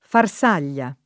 vai all'elenco alfabetico delle voci ingrandisci il carattere 100% rimpicciolisci il carattere stampa invia tramite posta elettronica codividi su Facebook Farsaglia [ far S# l’l’a ] tit. f. — anche, meno propr., var. del top.